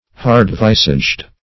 Search Result for " hard-visaged" : The Collaborative International Dictionary of English v.0.48: Hard-visaged \Hard"-vis`aged\ (h[aum]rd"v[i^]z`[asl]jd; 48), a. Of a harsh or stern countenance; hard-featured.
hard-visaged.mp3